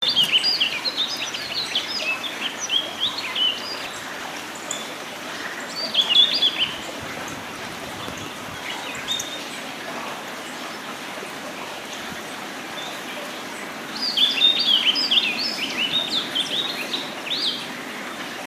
kasiradaka.mp3